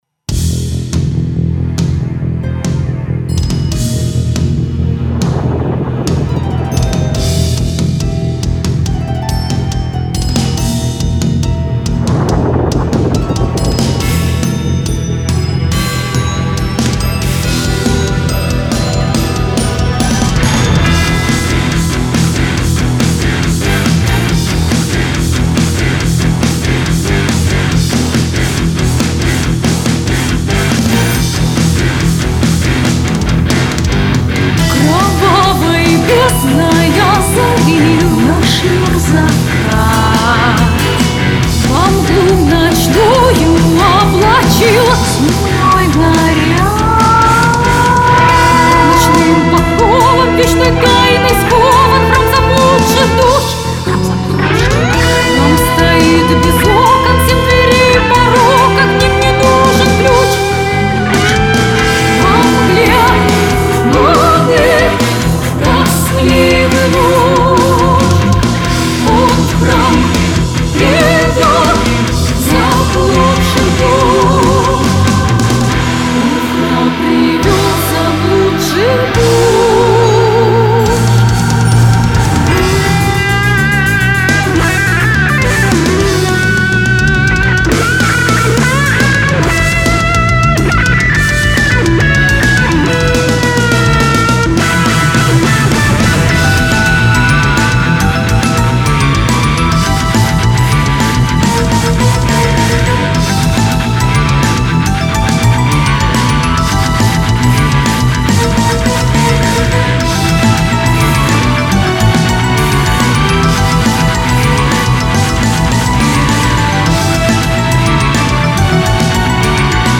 Symphonic Progressive Metal